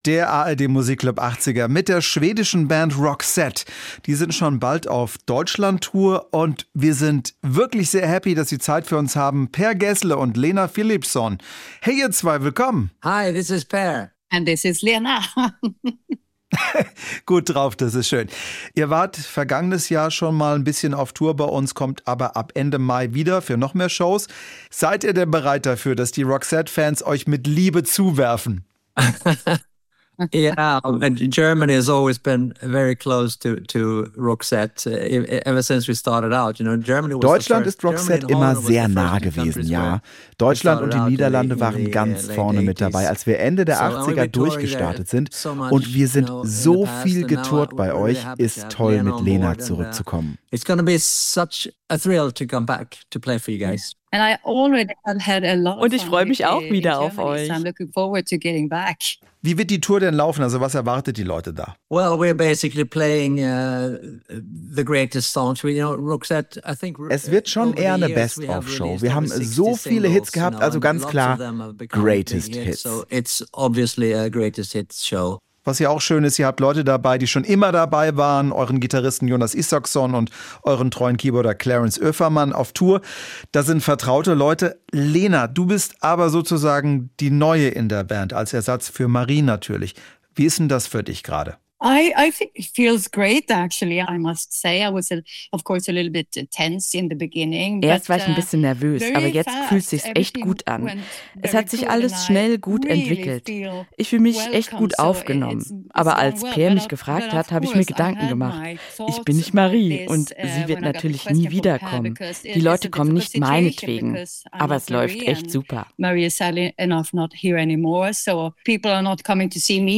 Neue Sängerin von Roxette im Interview über Deutschlandtour
Die schwedische Band Roxette ist bald wieder auf Deutschlandtour. Wir haben darüber mit Per Gessle und der neuen Sängerin Lena Philipsson gesprochen.
Interview mit